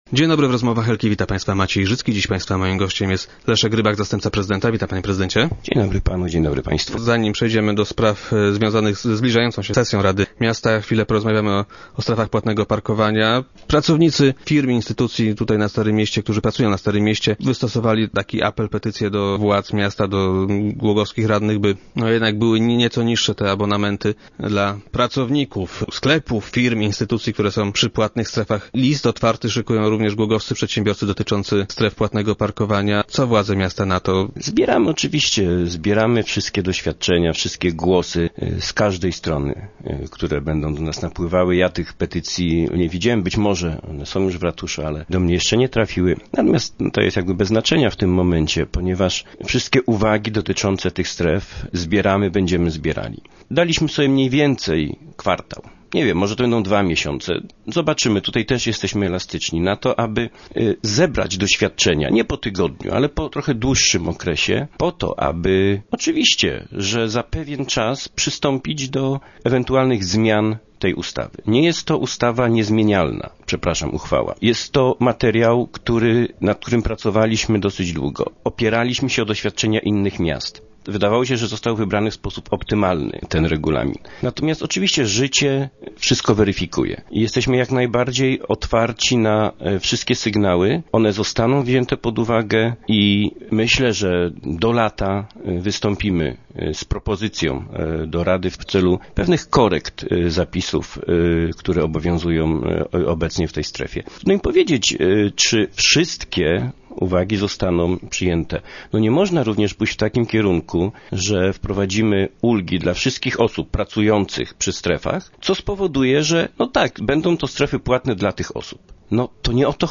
Jak zapewnia Leszek Rybak, zastępca prezydenta miasta, niewykluczone są zmiany w regulaminie stref.